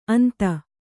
♪ anta